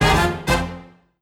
success.wav